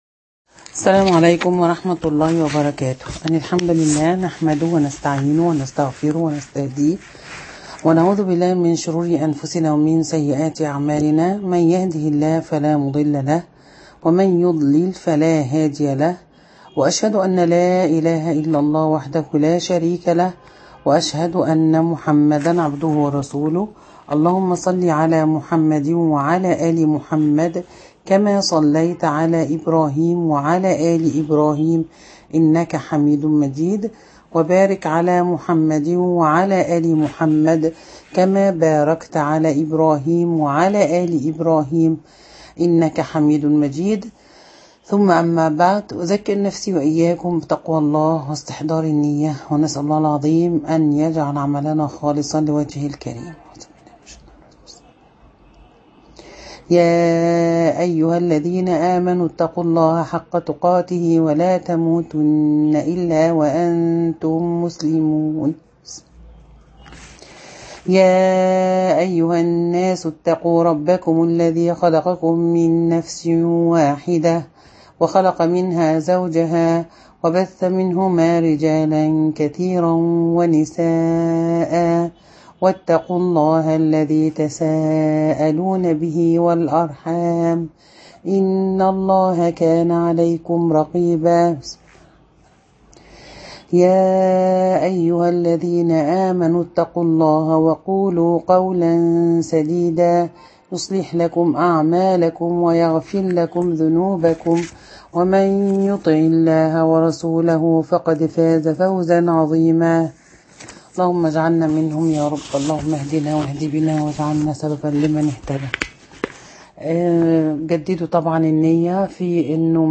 النحو_المحاضرة الثالثه